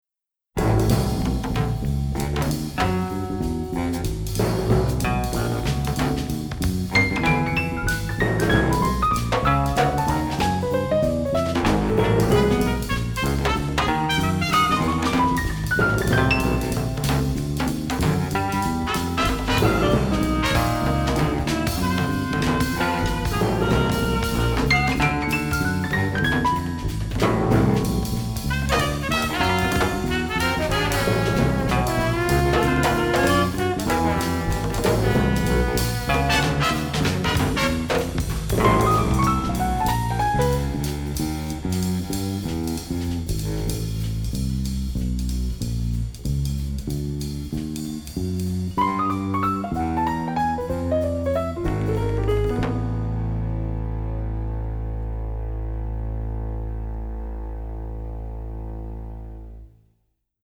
funk/jazz/groove